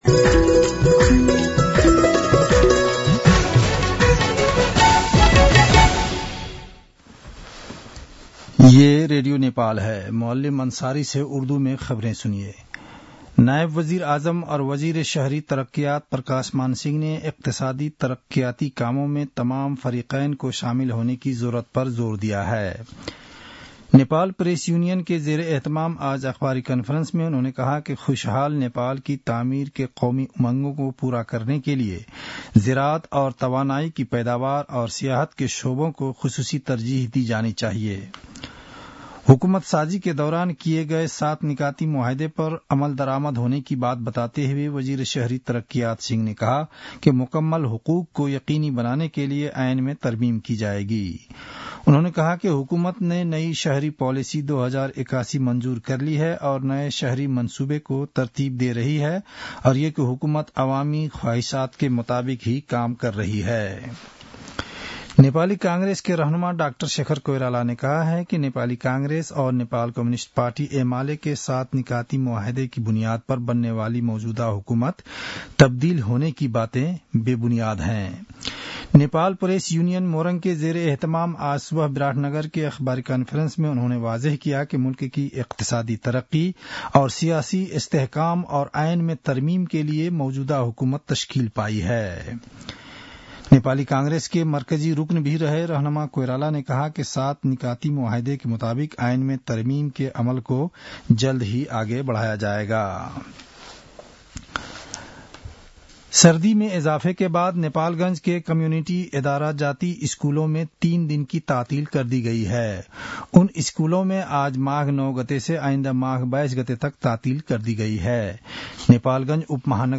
उर्दु भाषामा समाचार : १० माघ , २०८१